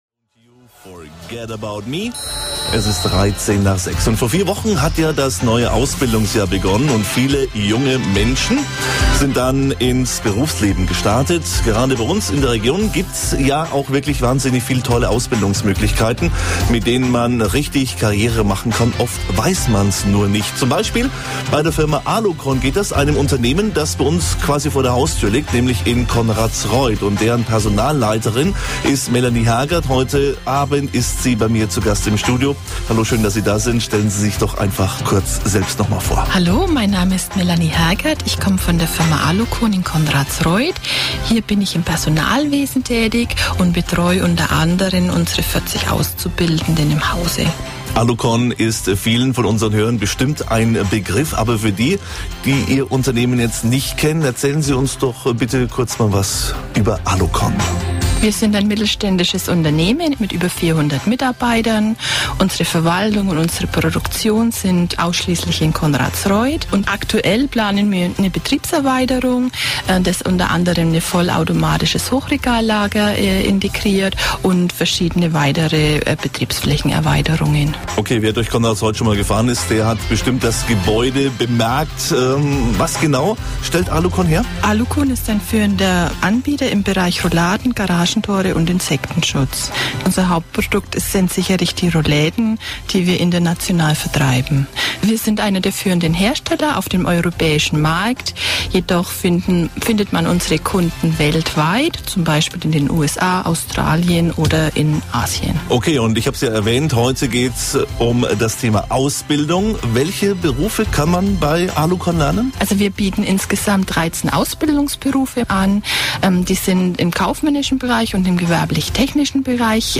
Anfangs waren beide noch etwas aufgeregt vor einem Mikro zu sprechen. Dieses „Lampenfieber“ legte sich allerdings nach kurzer Zeit und die Azubis erzählten über ihre Erfahrungen der Ausbildung bei ALUKON.
Neben der „harten Arbeit“ wurde viel gelacht und alle hatten Spaß.